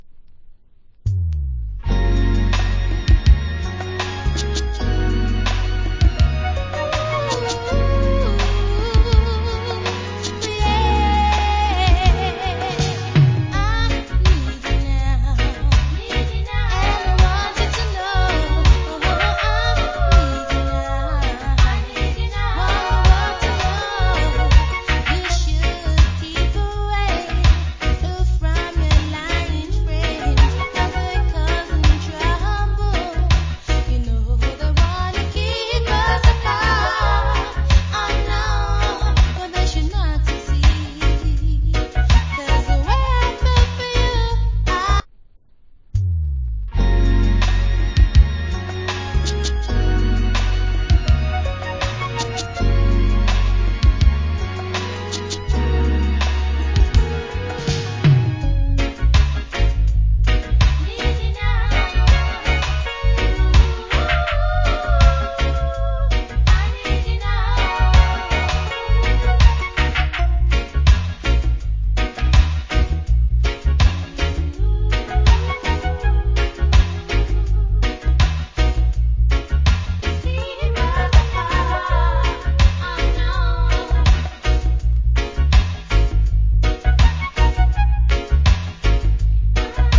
80's. Female UK Lovers.